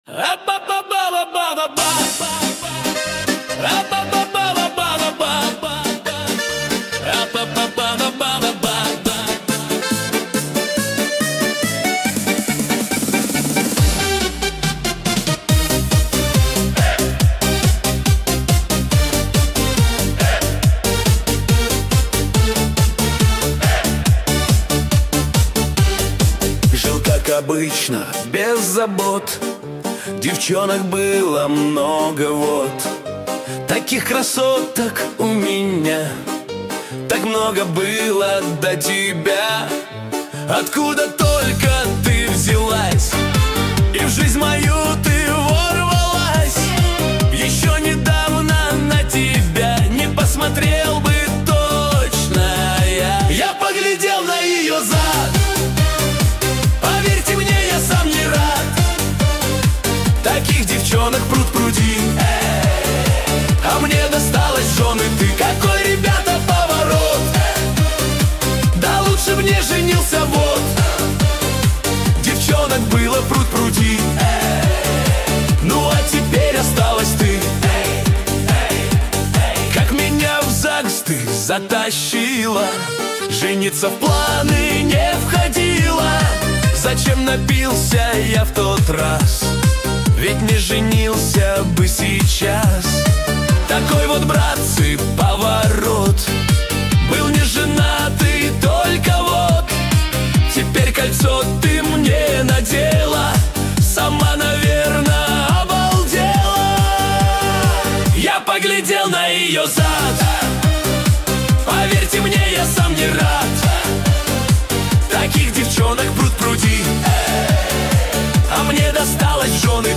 Качество: 192 kbps, stereo